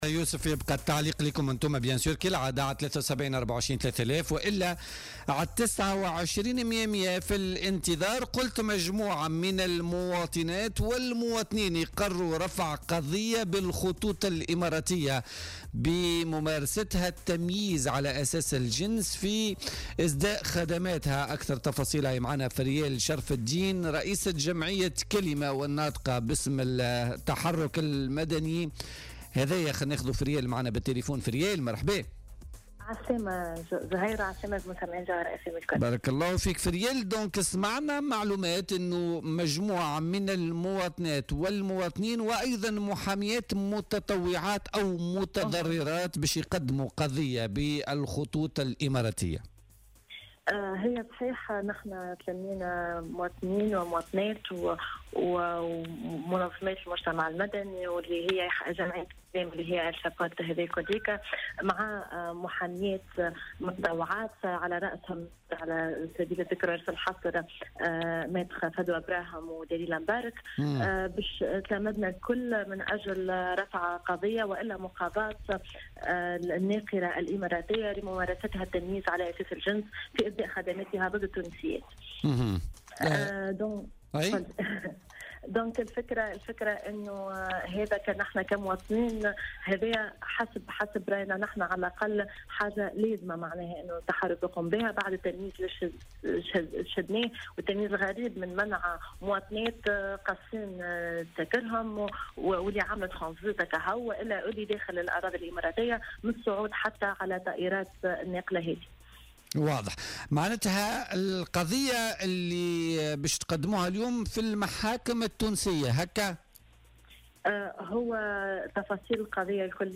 مداخلة لها في بولتيكا